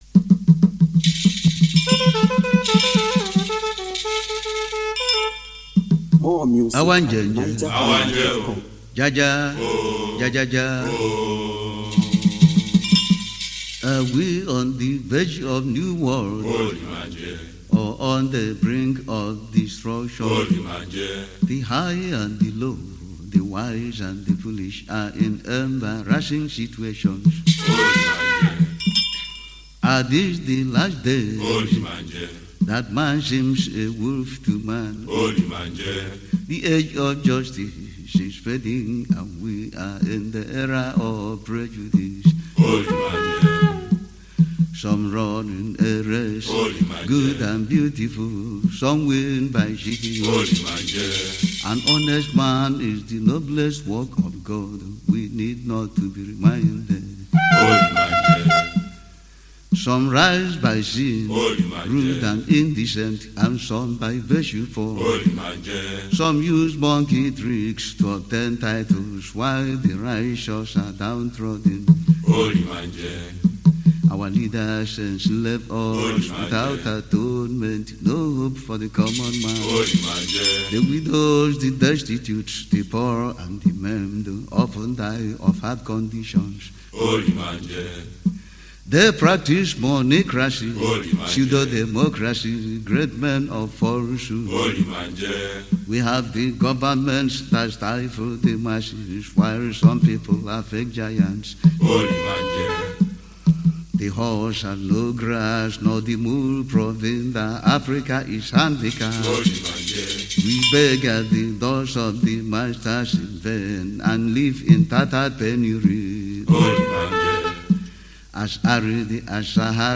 Home » Ogene